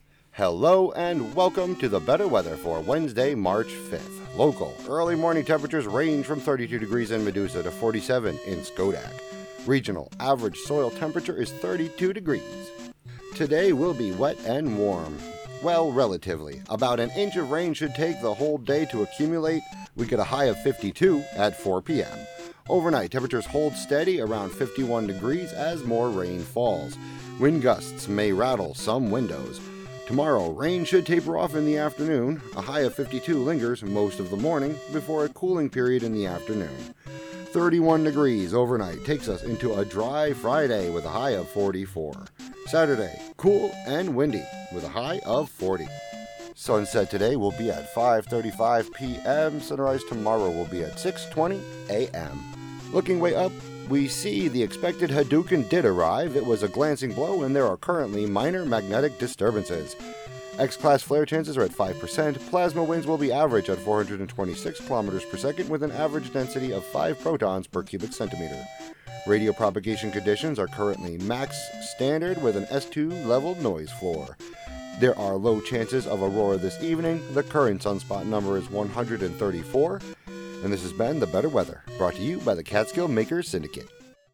meteorological predictions, pollen counts, Hudson River water temperatures, space weather, and more on WGXC 90.7-FM.